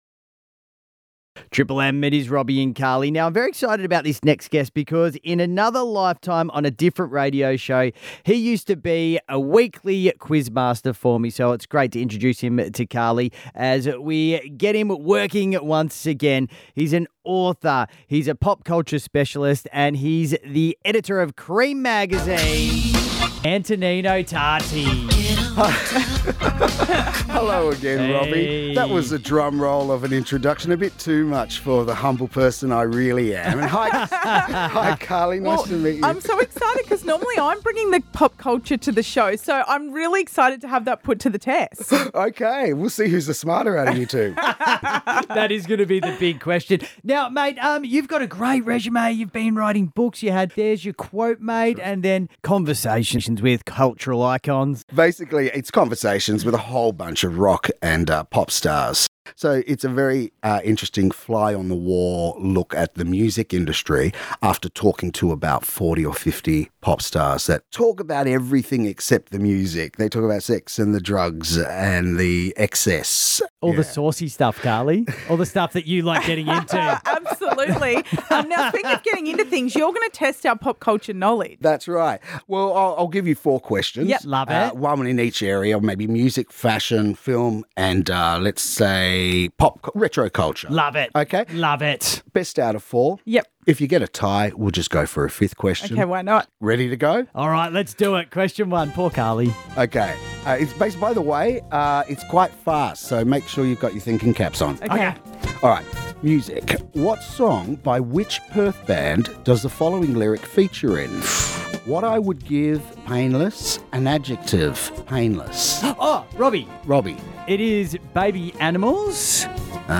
Exciting news on the radio front.